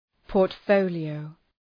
Προφορά
{pɔ:rt’fəʋlı,əʋ}